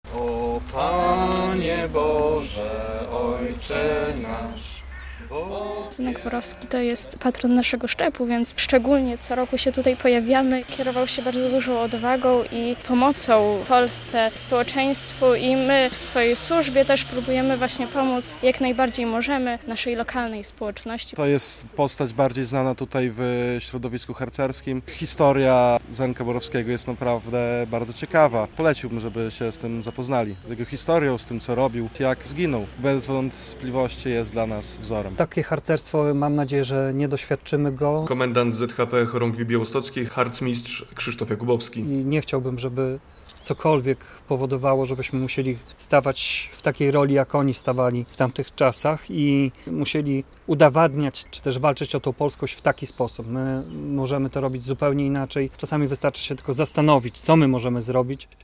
Było odśpiewanie modlitwy, zapalenie zniczy i wspomnienie bohatera. Białostoccy harcerze uczcili pamięć 13-letniego druha Zenka Borowskiego, zastrzelonego w czasie II Wojny Światowej przez Niemców za ratowanie polskich książek.